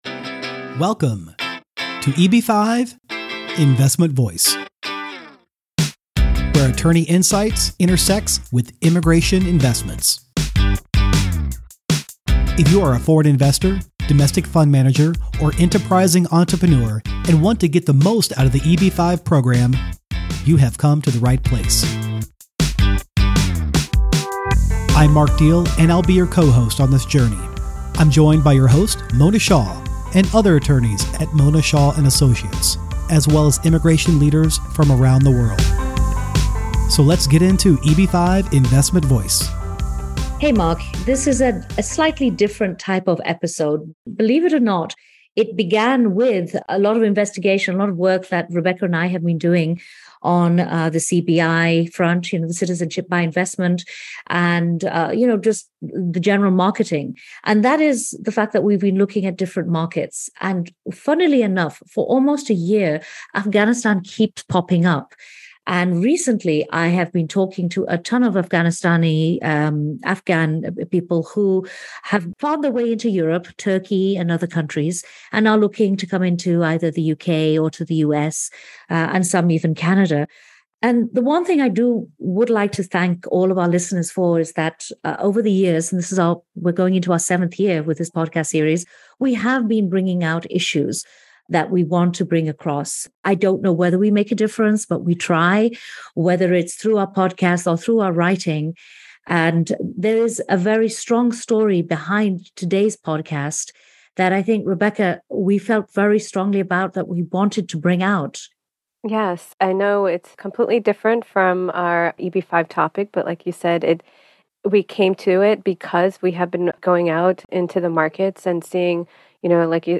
Realities Faced by Afghan Immigrants featuring Award Winning Political Journalists - Global Investment Voice